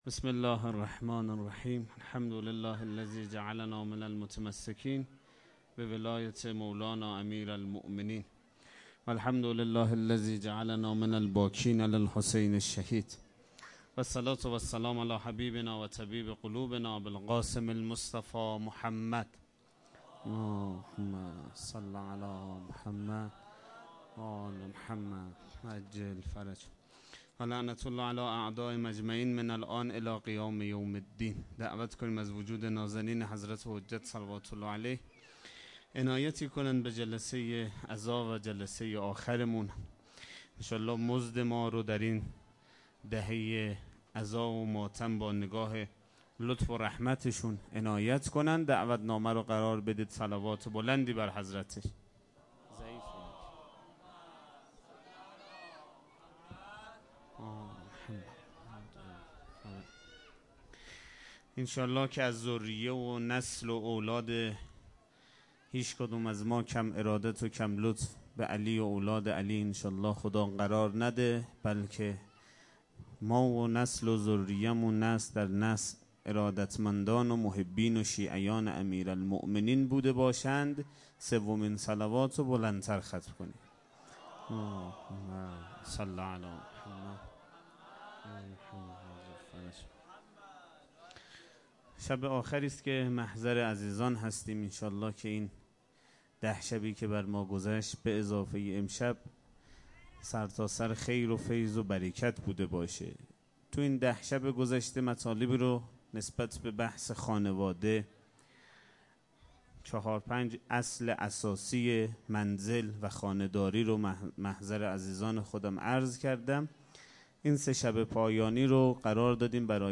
سخنرانی شب یازدهم محرم